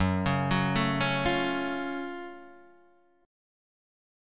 F# Minor Major 7th